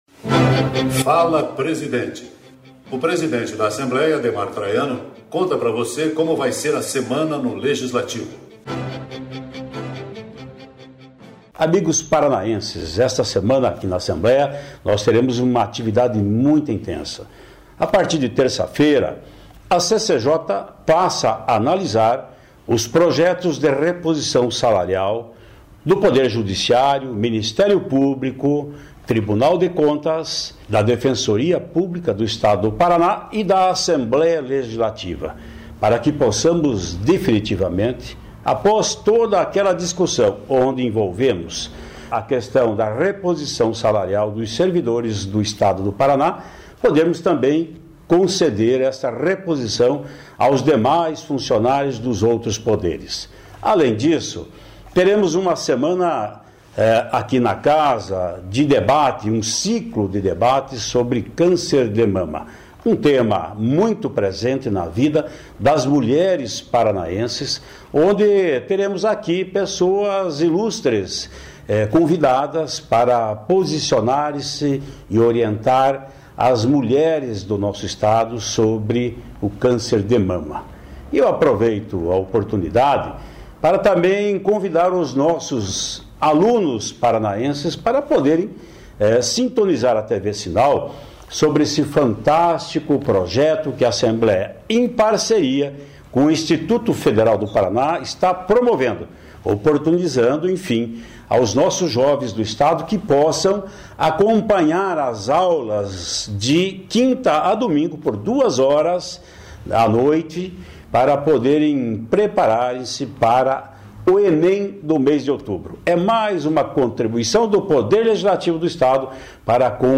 No "Fala Presidente" o deputado Ademar Traiano adianta os destaques da Assembleia nesta semana